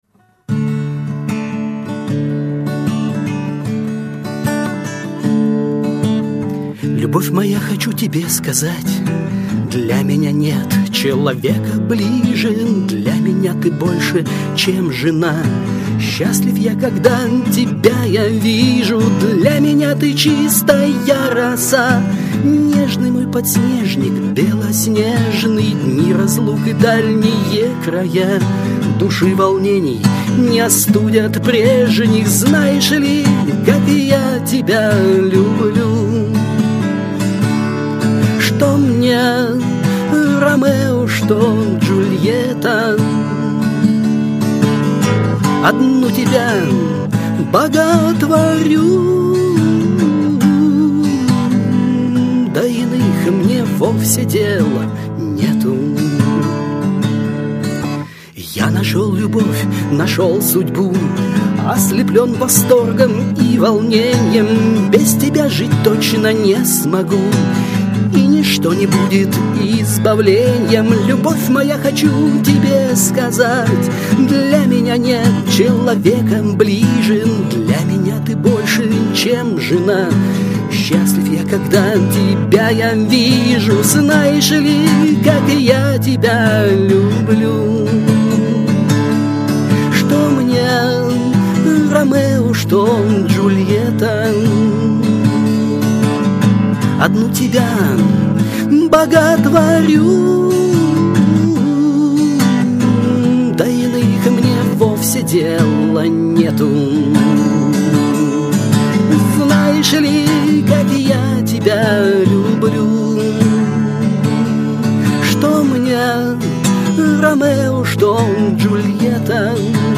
Армейские и дворовые песни под гитару